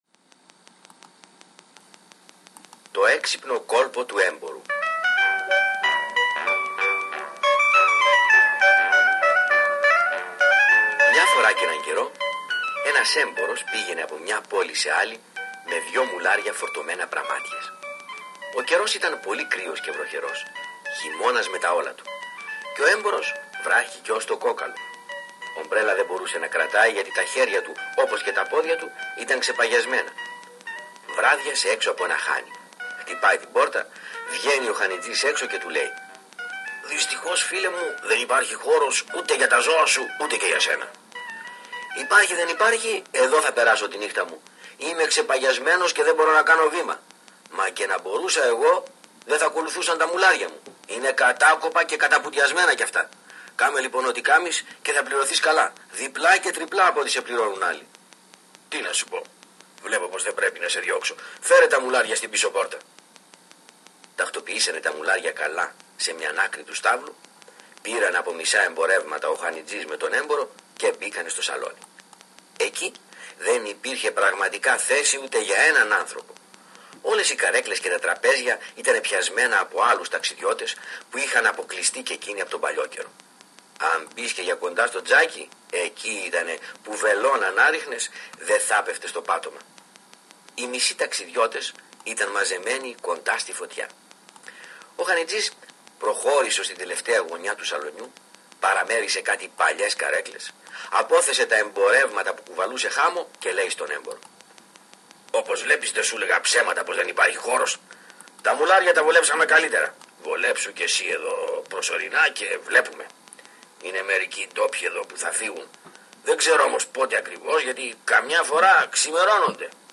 Επίπεδο: Β2 Δεξιότητα: Κατανόηση Προφορικού Λόγου
Ηθοποιοί: Αφηγητής, Χανετσής, Έμπορος